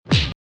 Title=coups_poing